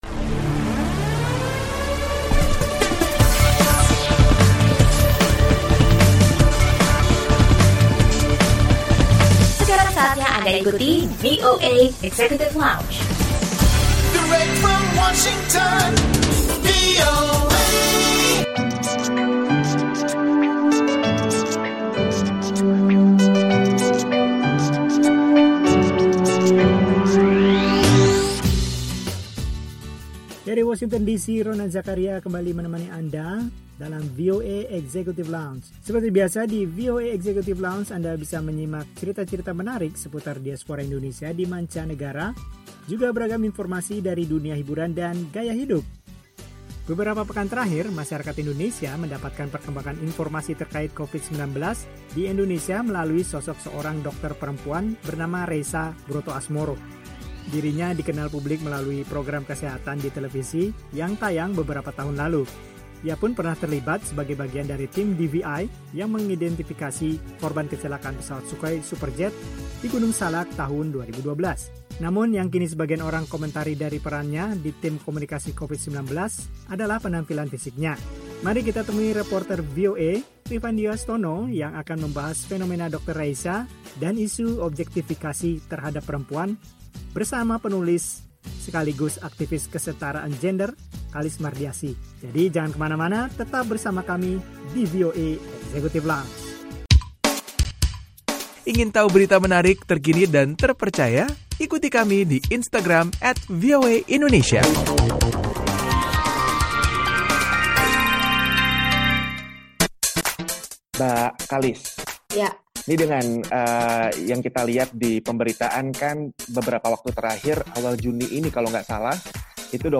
Obrolan dengan membahas fenomena dr. Reisa dan isu objektifikasi terhadap perempuan bersama penulis sekaligus aktivis kesetaraan gender